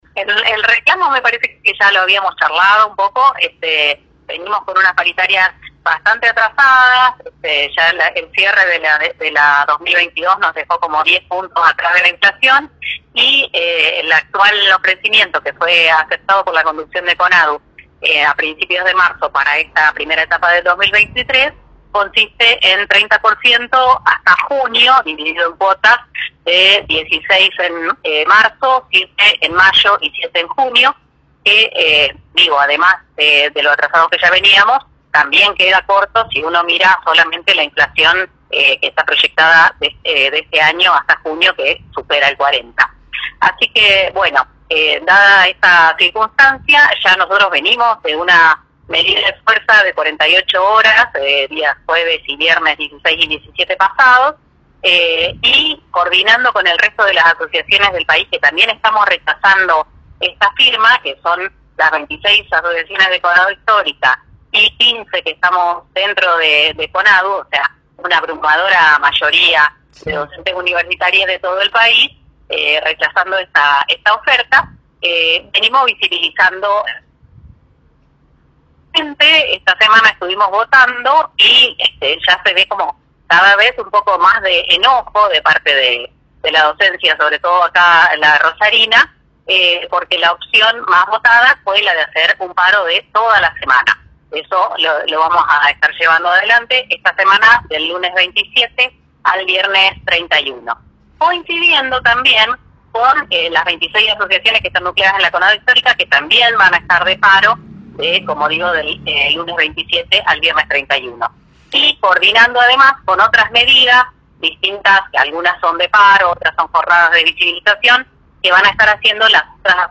En Primera Plana, por Cadena 3 Rosario